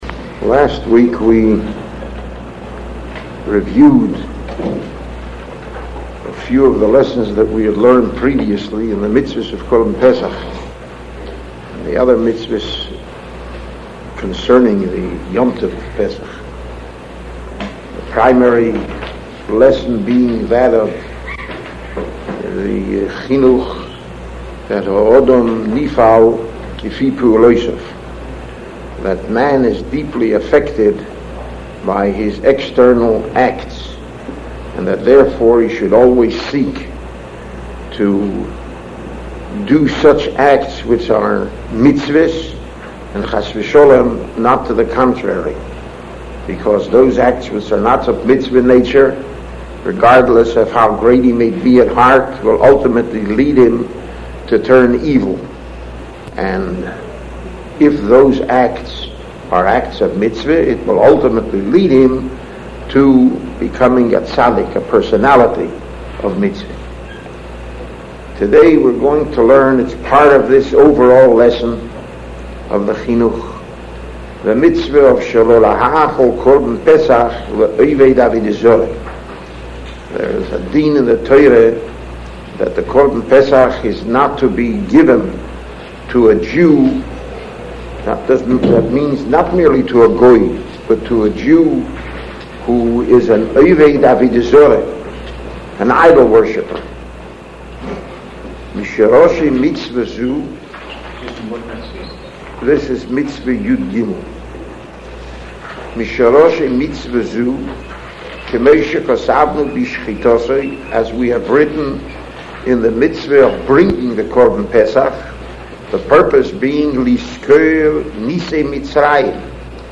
giving a shiur on Minchas Chinuch Sefer Shemos V.